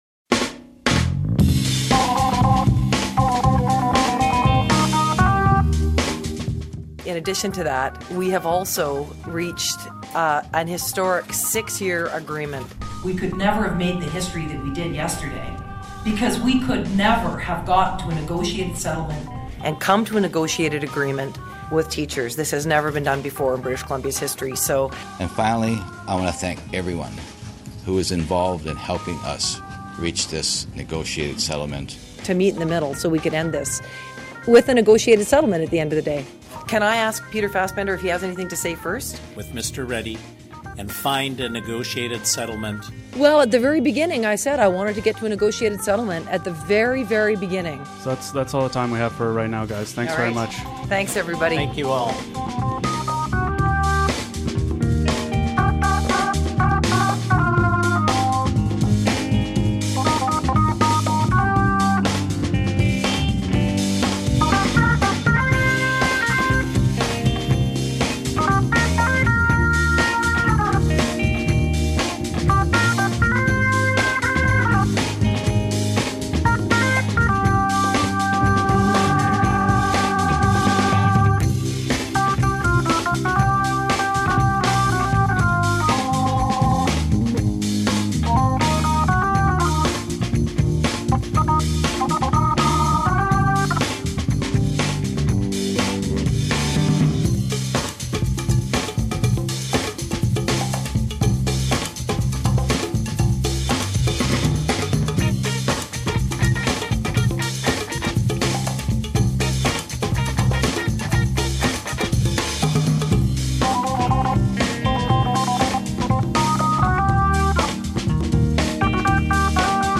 You're going to hear a number of people say what we think were the two talking points of the week.